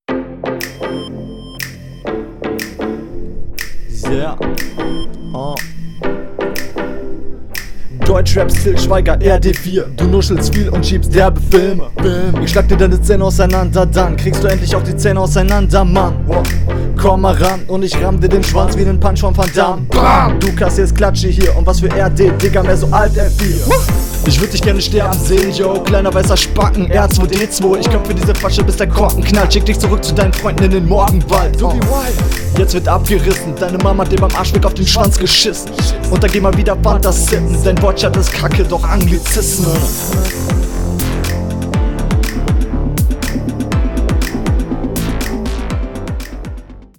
Flowlich hat das viel Variation, Stimmeinsatz echt cool, teilweise flowlich etwas raus, was wohl an …
Geiler Beat, aber leider stolperst du irgendwie drüber.